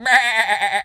sheep_baa_bleat_high_03.wav